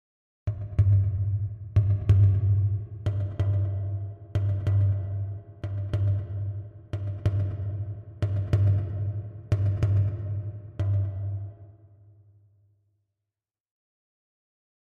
Drums Slow Hits 3 - Heart Beat